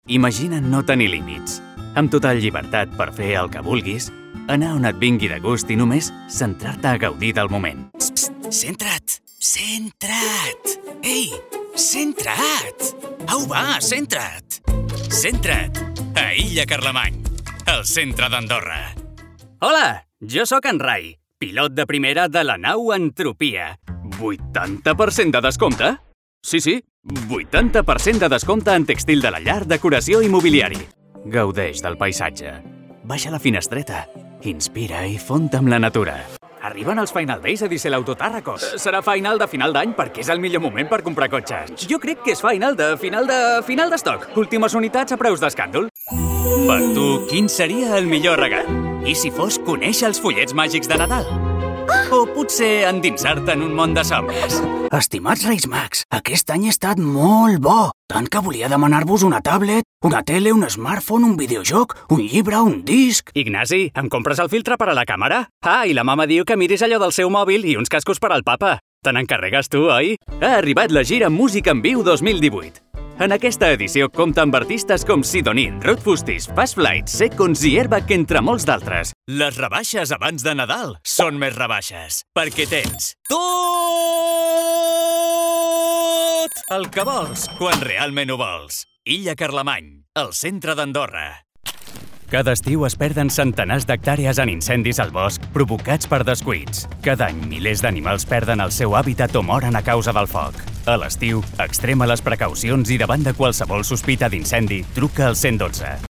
Jeune, Naturelle, Distinctive, Urbaine, Cool
Commercial